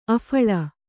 ♪ aphaḷa